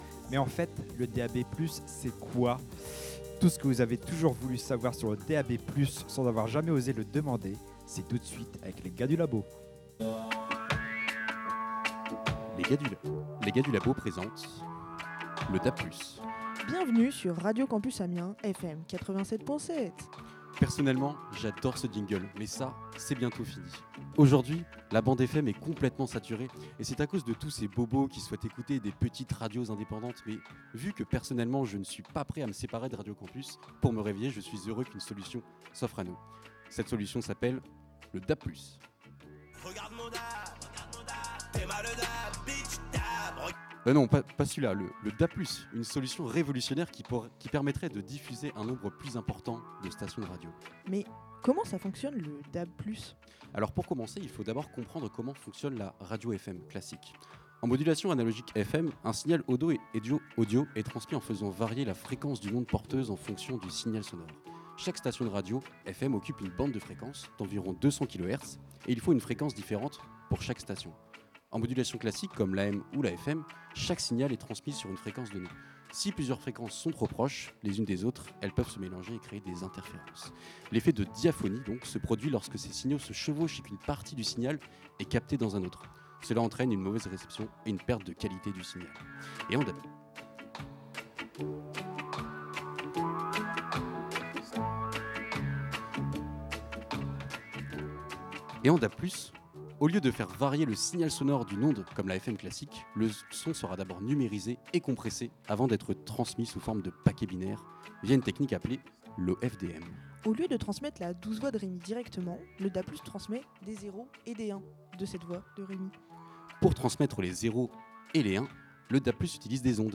Samedi 22 mars, les animateurs et animatrices Radio Campus sur scène au Chaudron - Scène étudiante du Crous et en direct !
Les Gars du labo en live nous expliquent ce qu’est le mystérieux DAB+ qui permet la diffusion de votre radio préférée